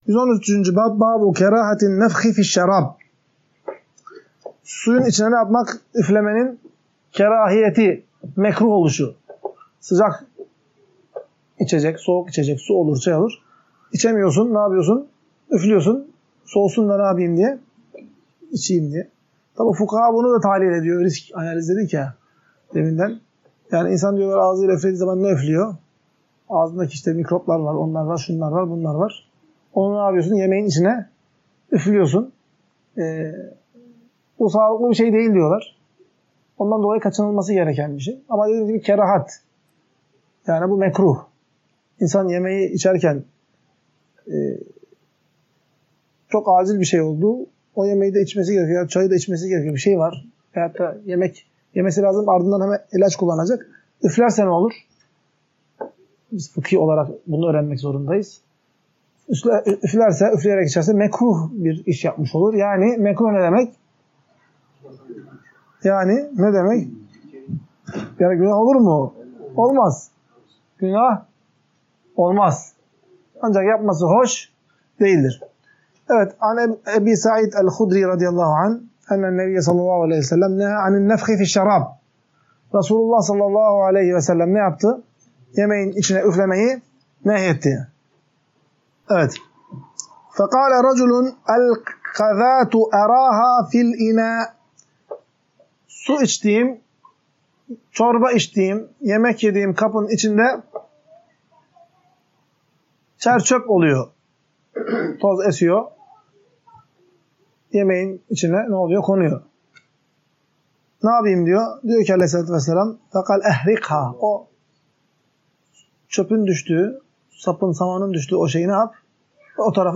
Ders - 14.